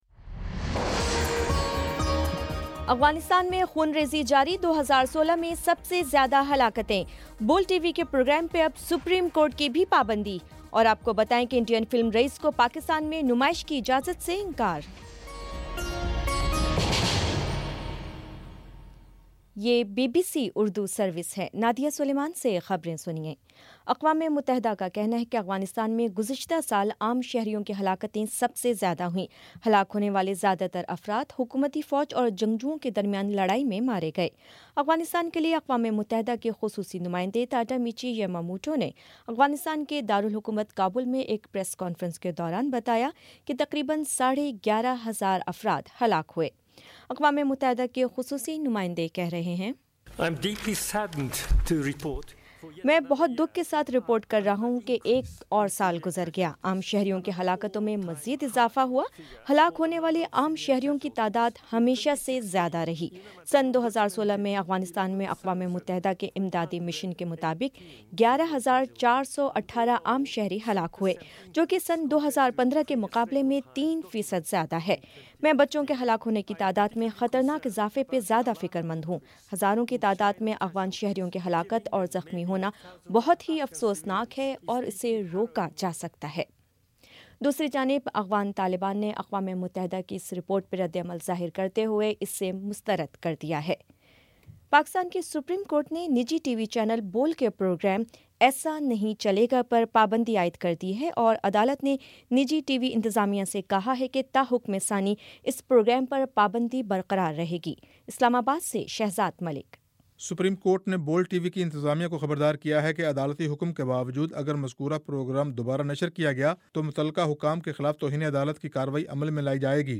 فروری 06 : شام چھ بجے کا نیوز بُلیٹن